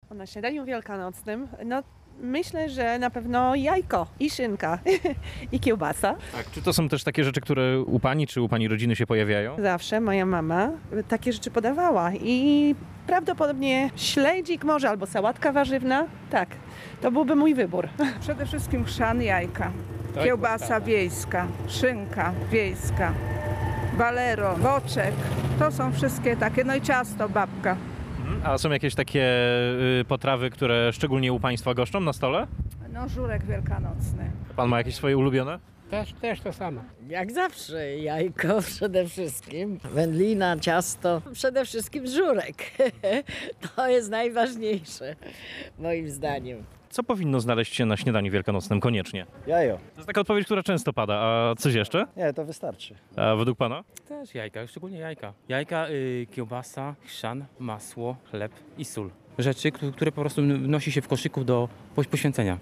Śniadanie wielkanocne. Co gości na naszych stołach? (sonda)
Co powinno znaleźć się na stole podczas śniadania wielkanocnego? O to zapytaliśmy mieszkańców Rzeszowa:
sonda.mp3